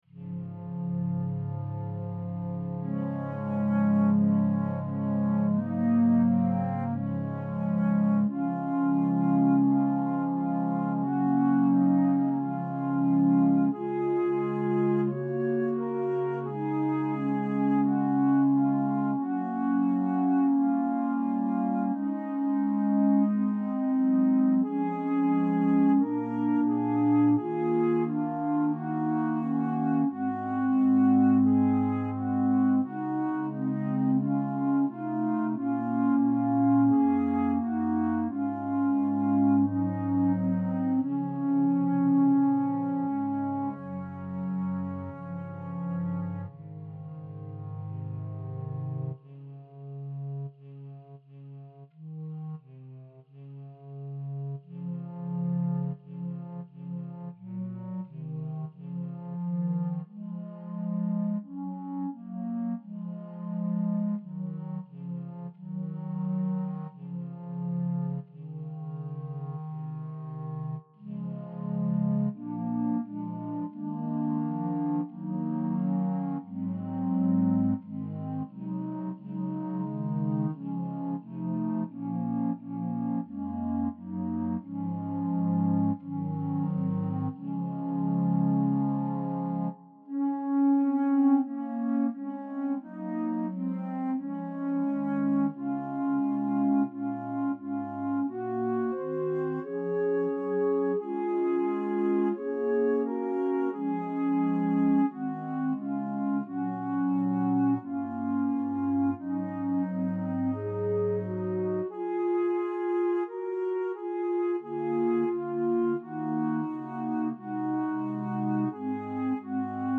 SATB - Beautiful Savior
Voicing/Instrumentation: SATB We also have other 47 arrangements of " Beautiful Savior ".